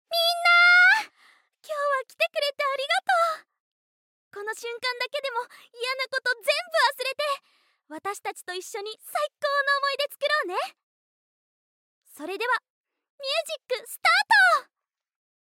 ボイスサンプル
アイドル